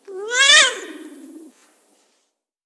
• Cat meow with slight purr.wav
Cat meowing with a slight purr at the end.
cat-meow-with-slight-purr_tFf.wav